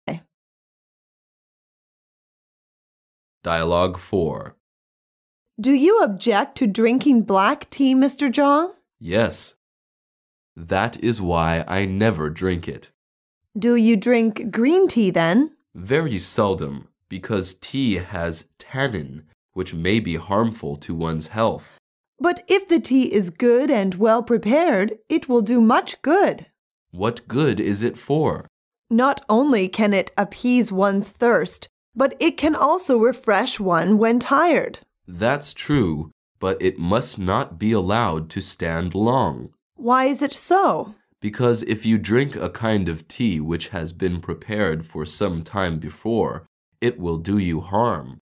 Dialouge 4